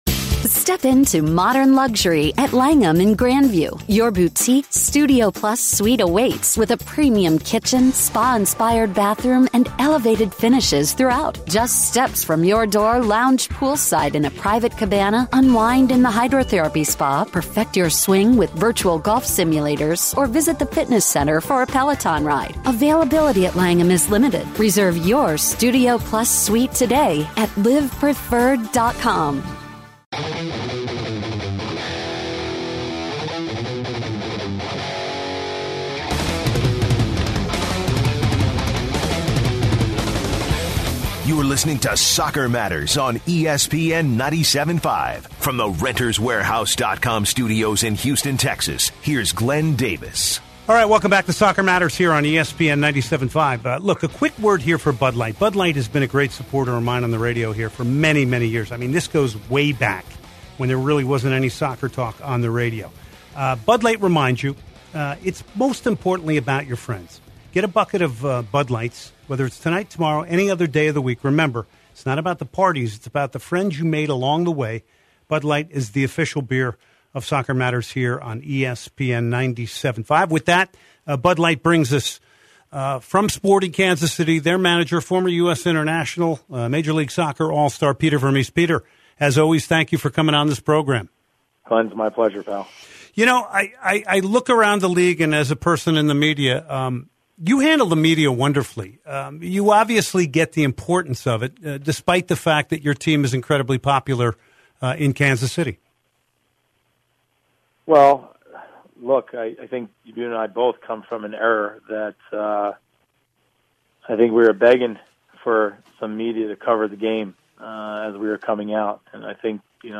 10/09/2017 Peter Vermes Interview